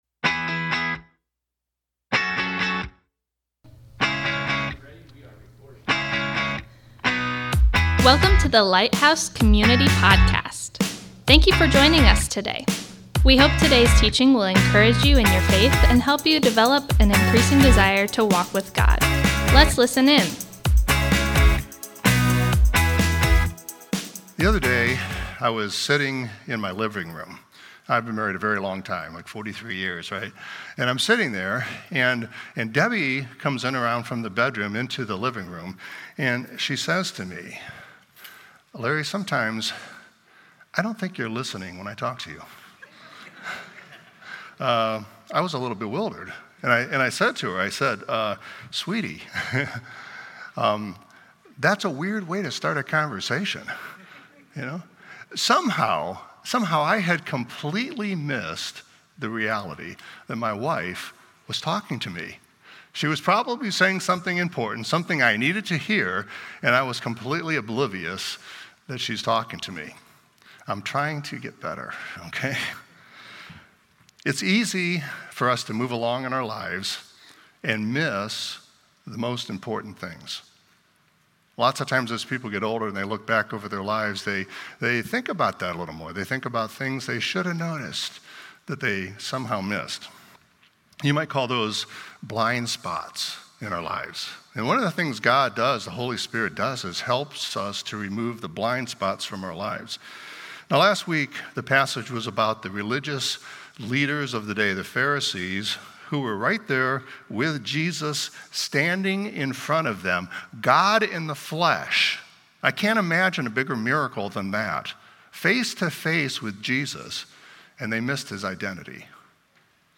Thank you for joining us today as we come together to worship!